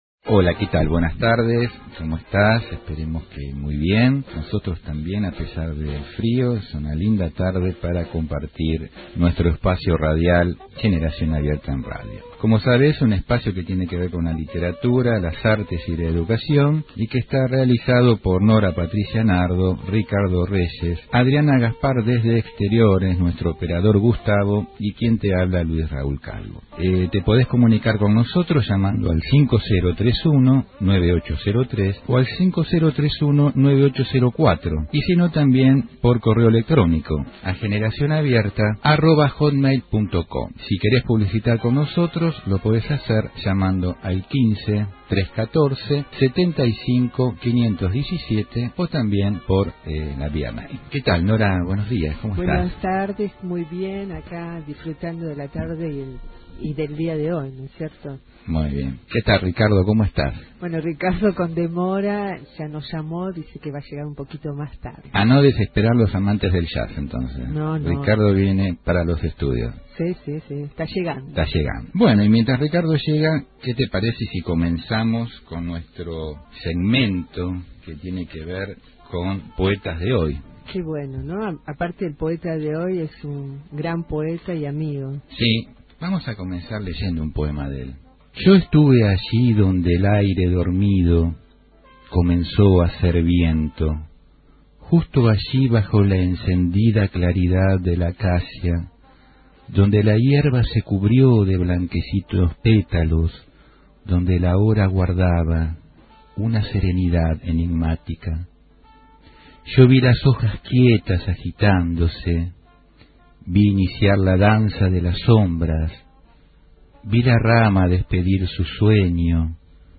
Por la Radio AM 1010 “Onda Latina” , Buenos Aires, Argentina.